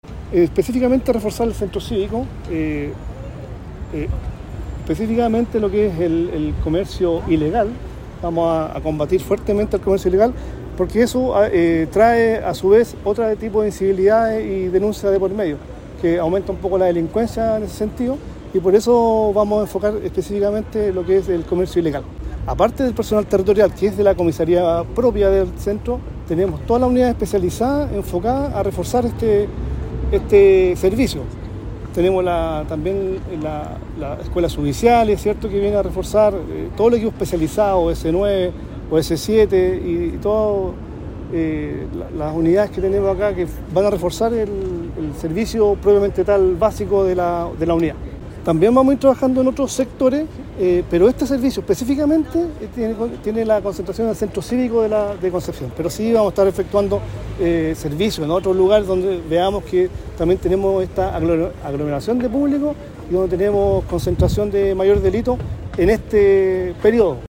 En una ceremonia efectuada en la Plaza Independencia de Concepción, autoridades regionales hicieron entrega formal de 33 nuevos vehículos policiales que serán distribuidos en distintas comunas de la Región, y destacaron que estos recursos logísticos reforzarán la base de la labor policial, los patrullajes, y la operatividad general de Carabineros en el territorio.
Por su parte, el jefe de Zona Biobío, general Renzo Miccono, señaló que las labores de fiscalización estarán enfocadas en el centro cívico de Concepción y el comercio ilegal, aunque también estarán pendientes de otras zonas donde se genere aglomeración de público.